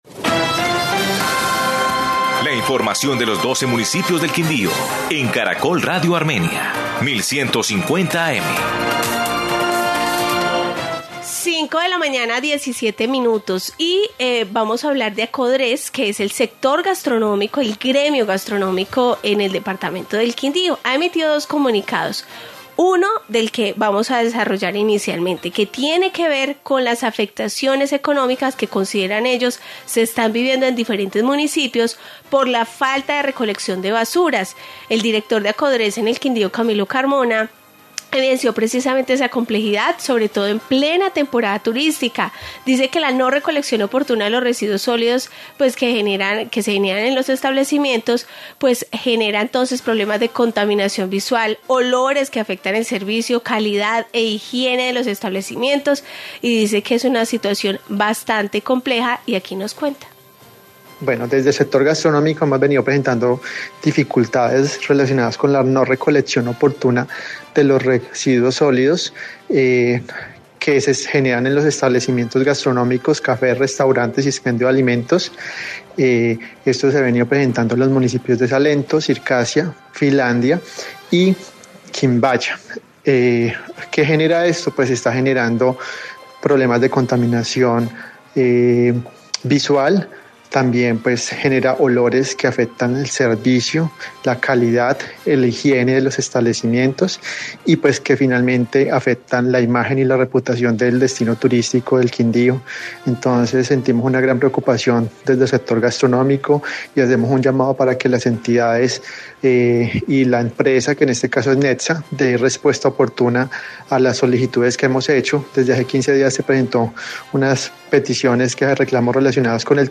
Informe sobre basuras y agua